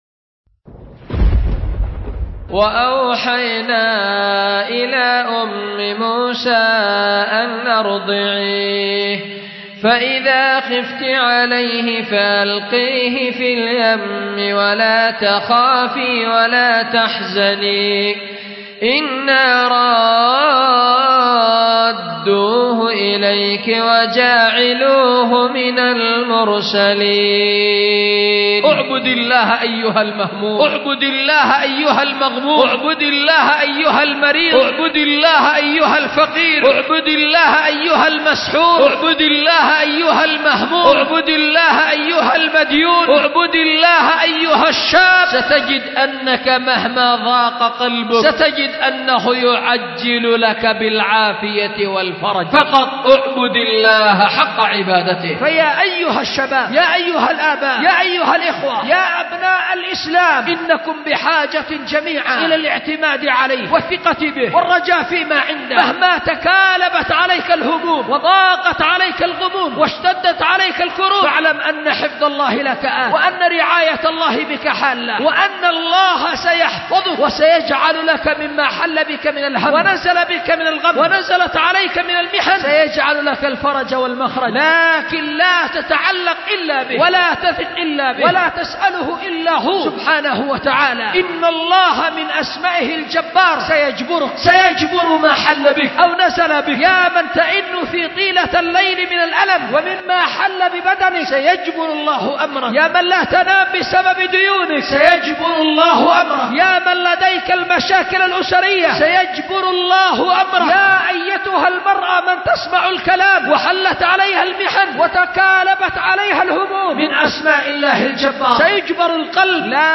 خطبة
أُلقيت بدار الحديث للعلوم الشرعية بمسجد ذي النورين ـ اليمن ـ ذمار